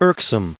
1632_irksome.ogg